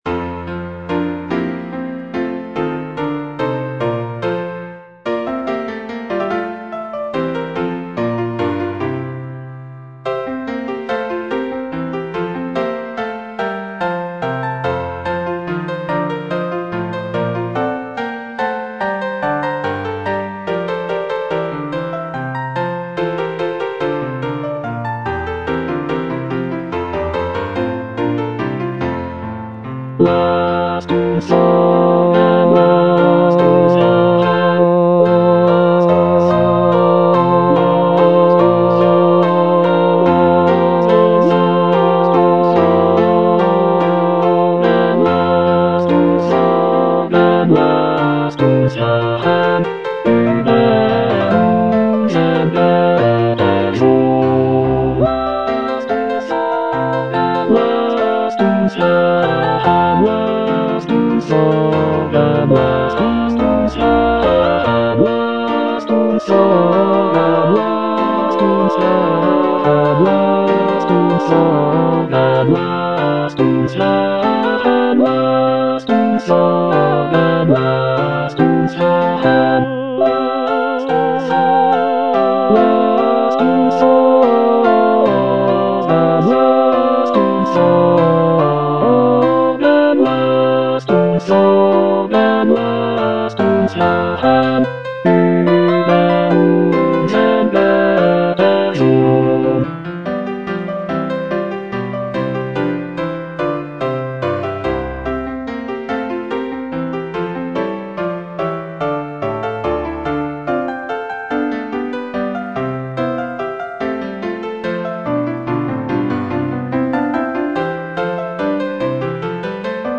Choralplayer playing Cantata
Bass (Emphasised voice and other voices) Ads stop